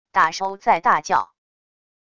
打手在大叫wav音频